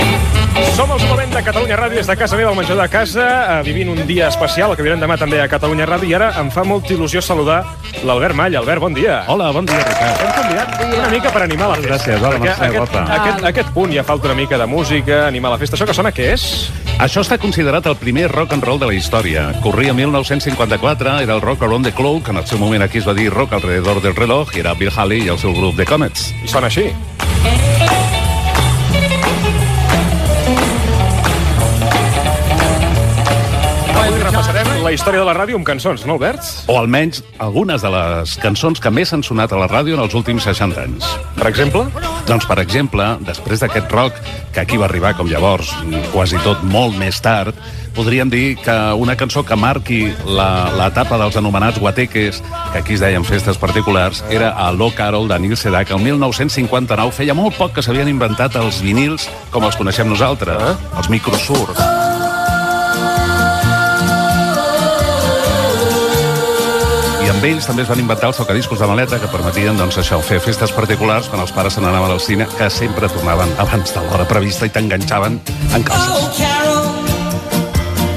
Ricard Ustrell presenta el programa des de casa seva amb motiu del Dia Mundial de la Ràdio 2017.
Entreteniment
FM